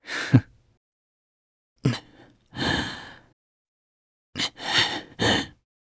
Roi-Vox_Sad_jp.wav